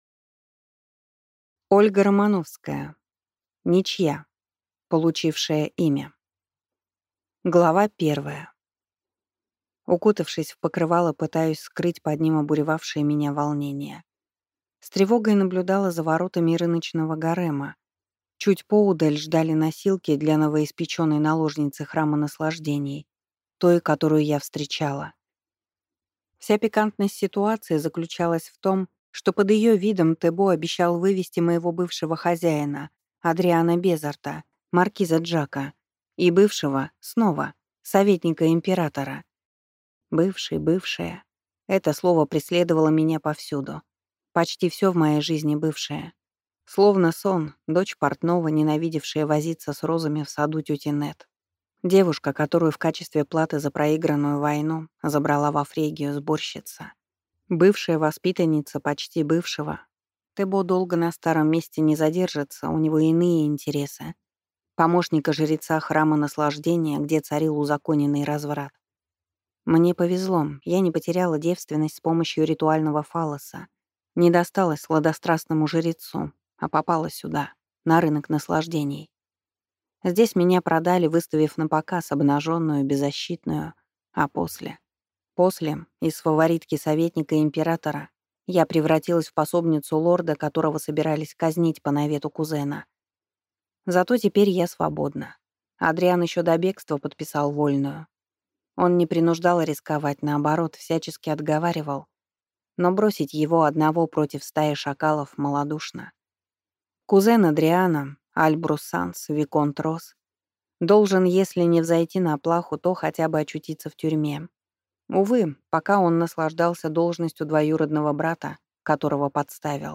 Аудиокнига Ничья 2 | Библиотека аудиокниг